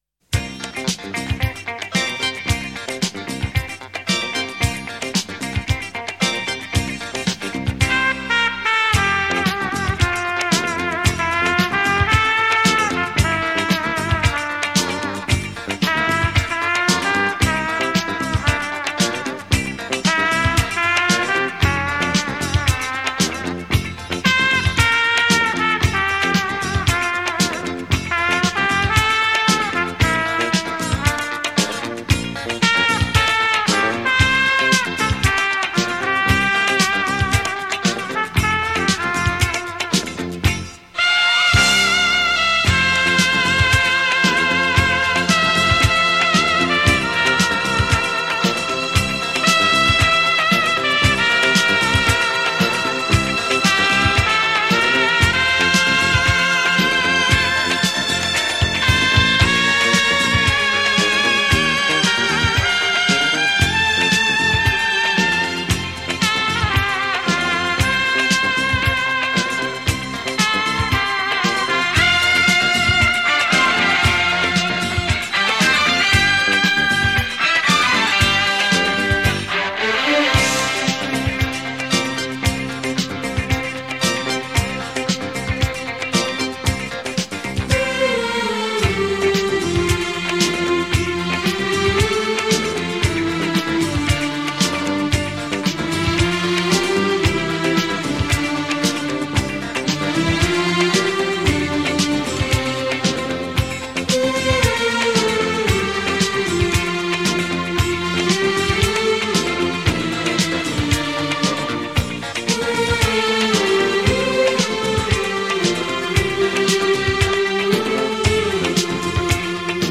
17首优美的小号轻音乐，荡气回肠，润人心府。